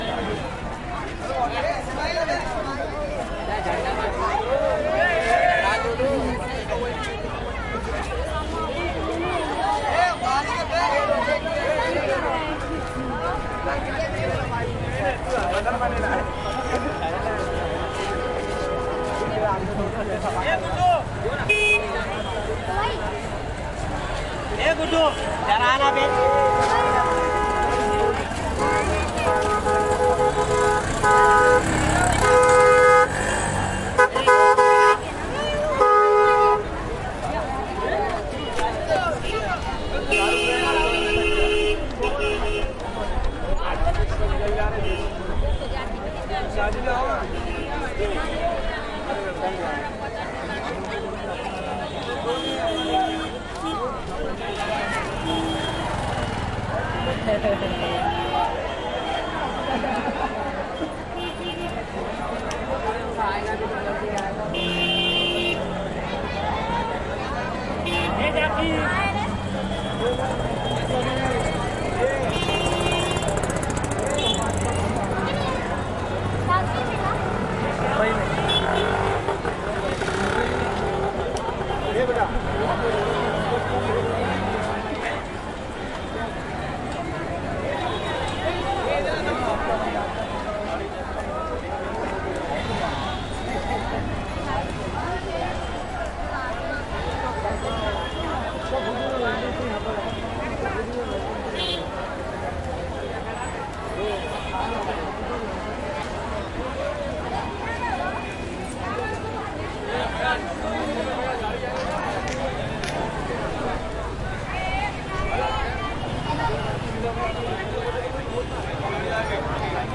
印度 " 车水马龙的喇叭声和警察的口哨声在附近的街道上回响。
描述：交通沉重的喉咙鸣喇叭和警察口哨听到附近的街道India.flac回声
标签： 警方 口哨 交通 街道 听到喇叭 印度 honks 嘶哑
声道立体声